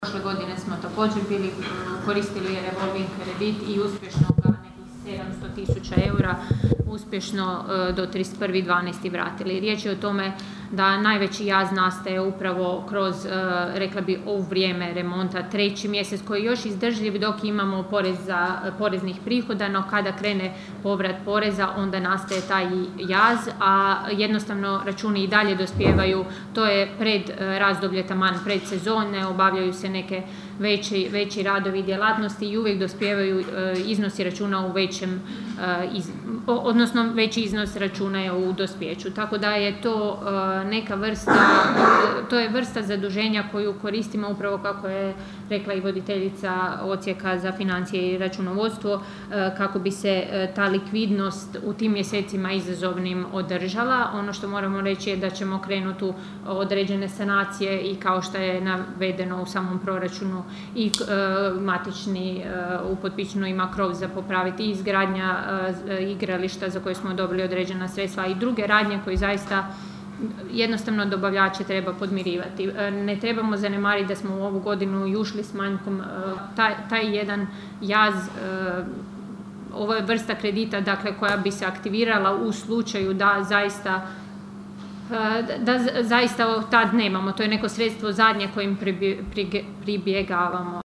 Na sinoćnjoj sjednici Općinskog vijeća Kršana vijećnici nisu donijeli odluku o milijun eura revolving kreditu, tražeći detaljniju analizu i obrazloženje.